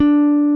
D4 1 F.BASS.wav